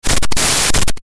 Digital Glitch Sound Effect
Short electronic noise effect, perfect for games or videos to depict device errors, interruptions, or malfunctions.
Digital-glitch-sound-effect.mp3